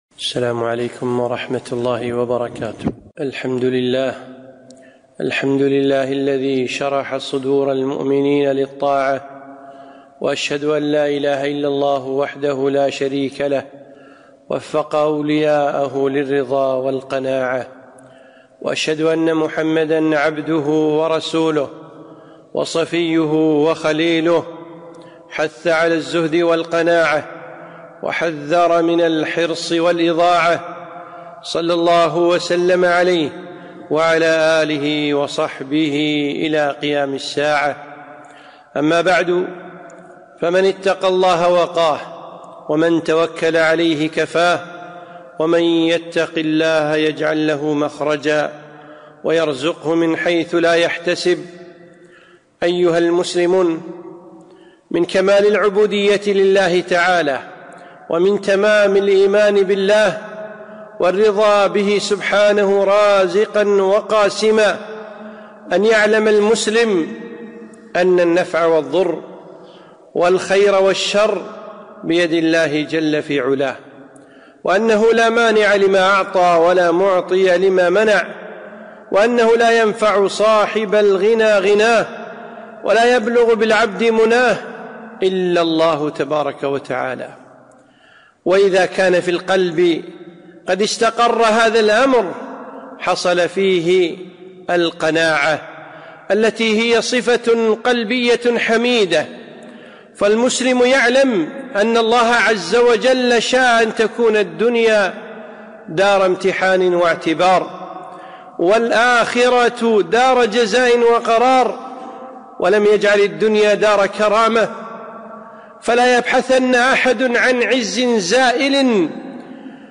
خطبة - القناعة